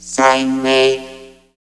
68.2 VOCOD.wav